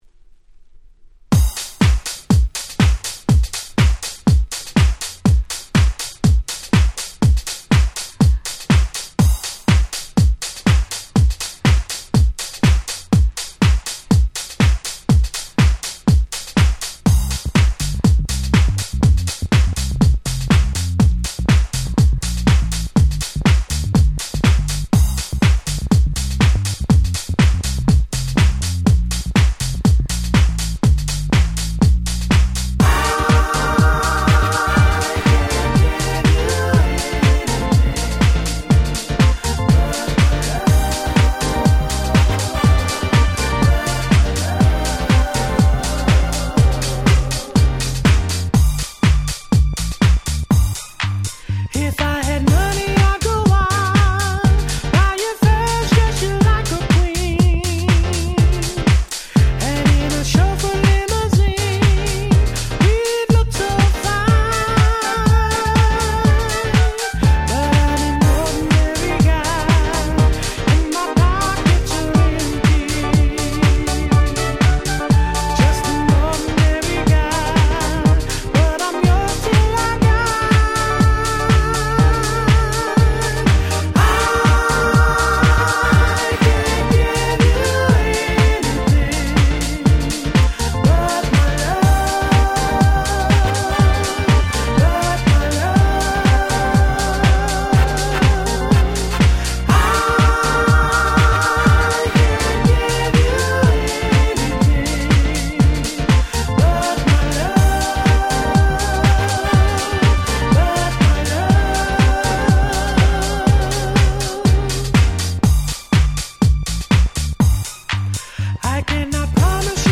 99' Very Nice Cover Vocal House !!
キャッチーで最高！！